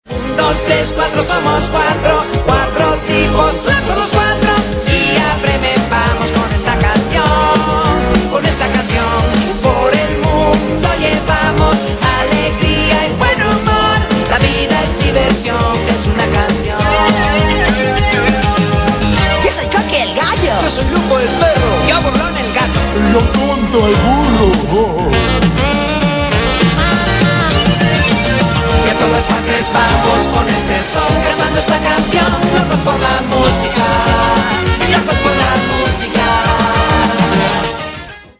Canción de la serie infantil de dibujos animados